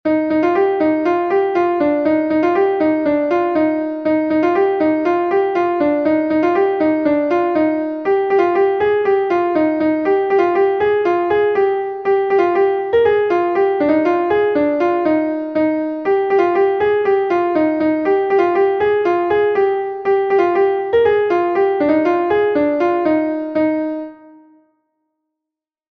Gavotenn Berne is a Gavotte from Brittany